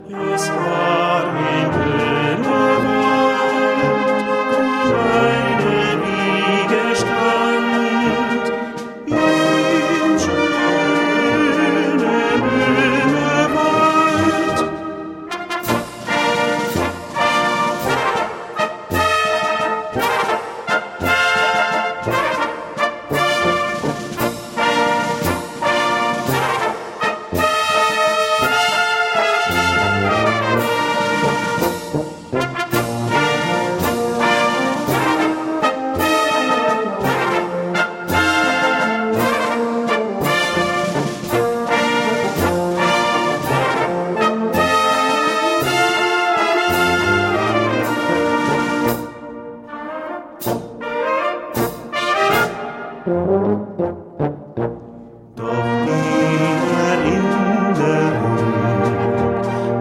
Gattung: Walzer
Besetzung: Blasorchester